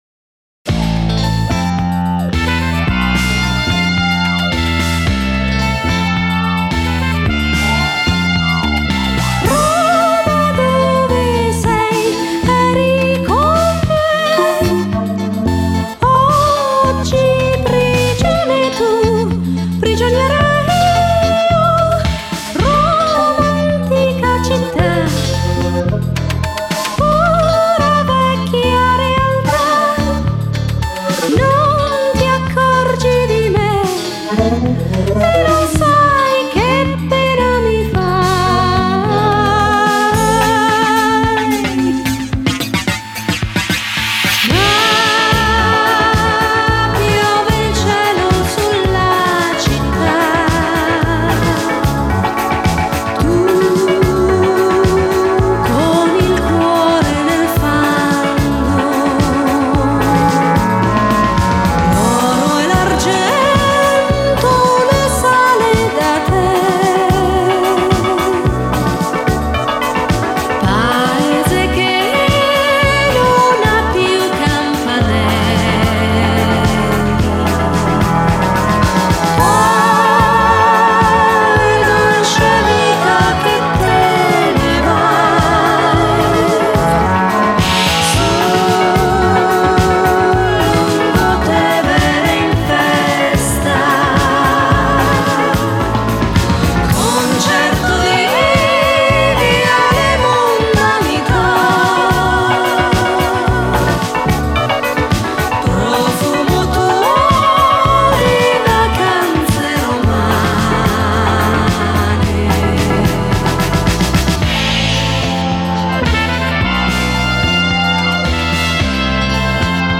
Genre: Pop, Pop-Rock, New Wave, Electronic